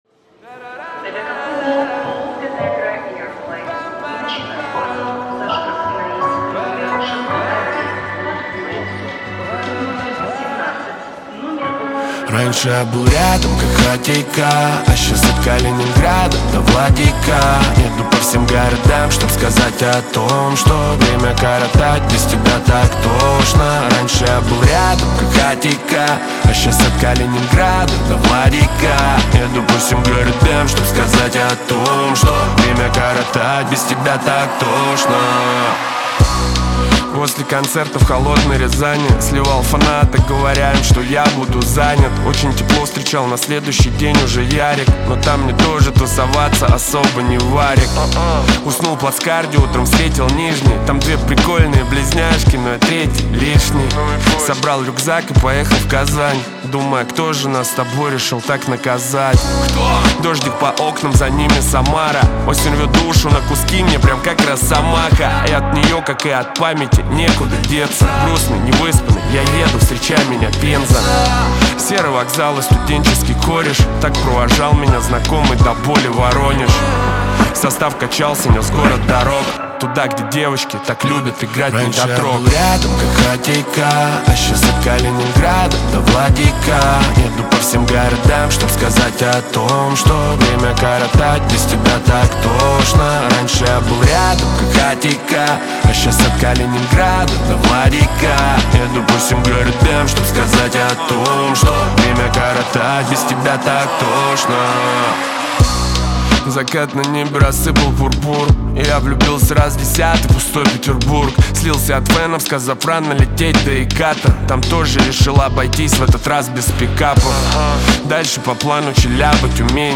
Трек размещён в разделе Русские песни / Рэп и хип-хоп.